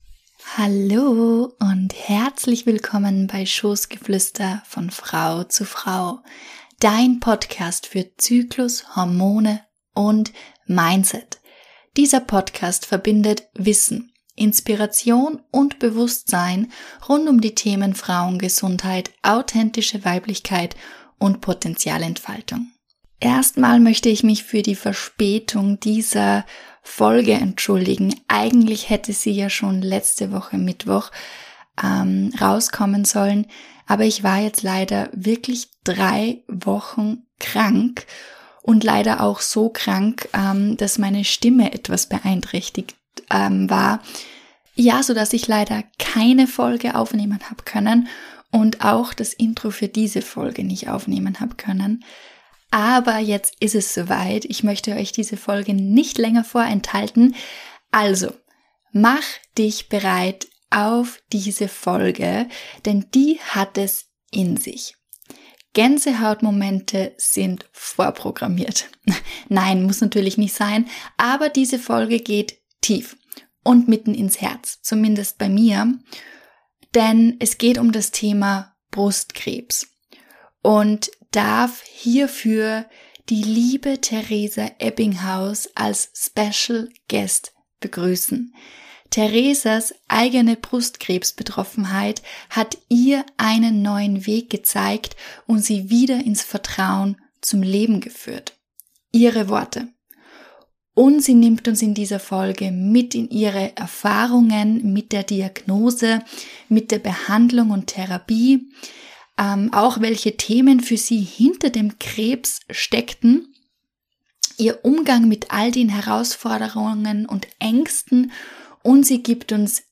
In diesem Gespräch erwartet dich: